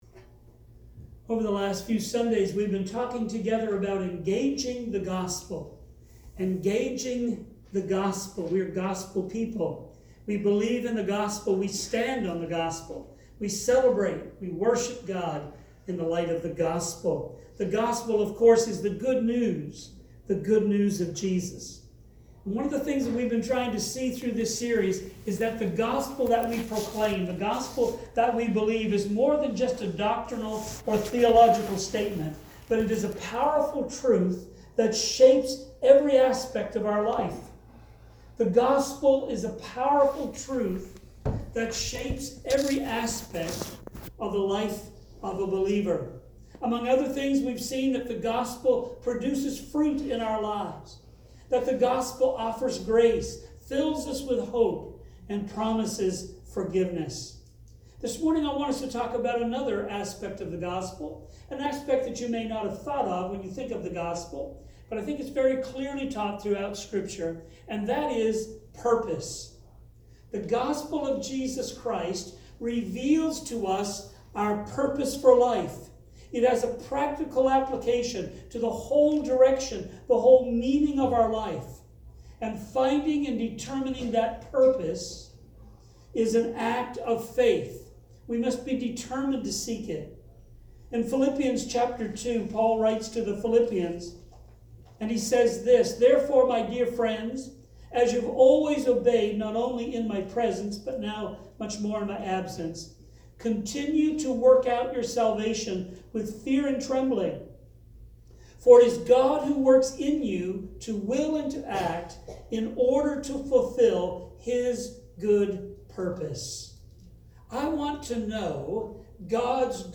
Nov 10 sermon-1